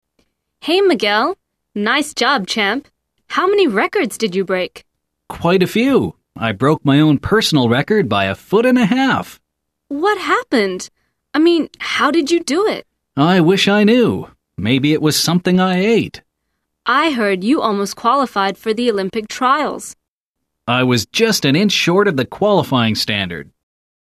聽力大考驗：來聽老美怎麼說？